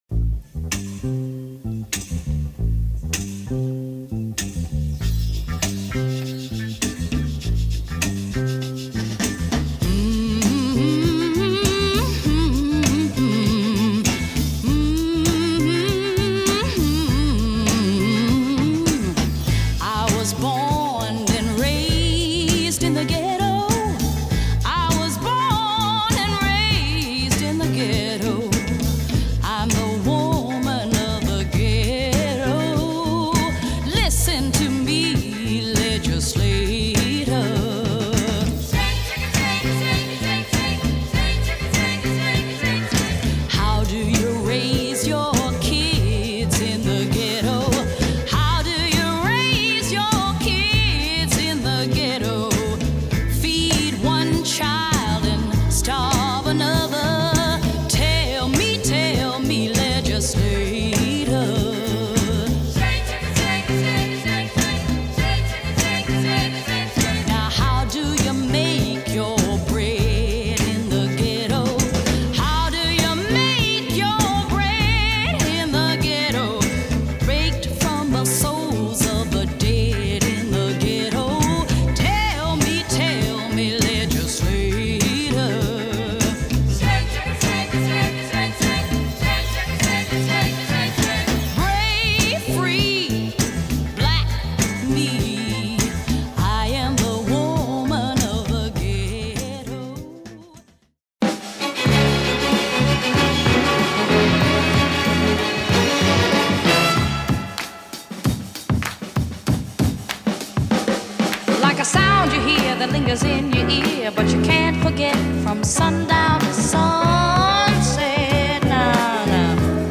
Marvellous soul classic !